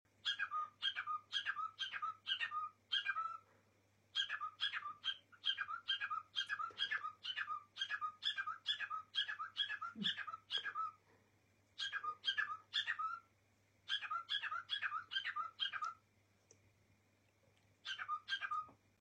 talking # cockatiel sound effects free download